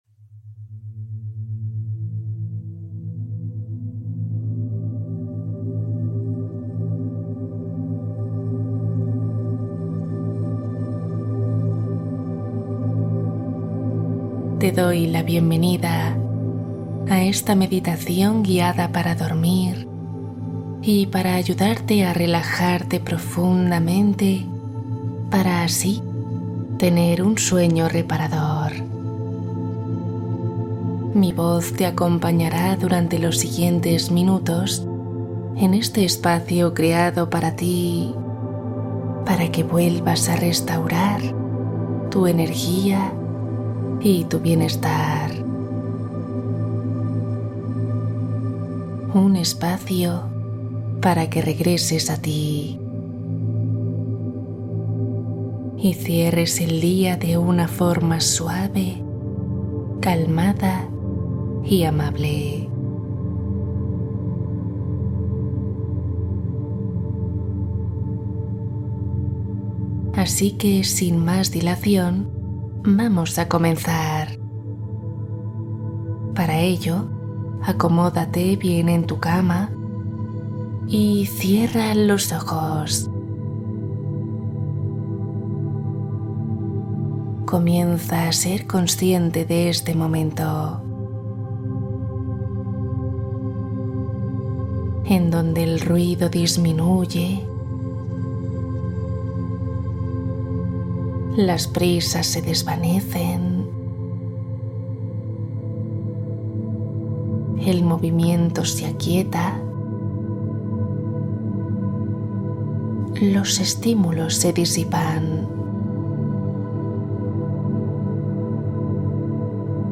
Cuento + Meditación Para dormir profundamente y relajar cuerpo–mente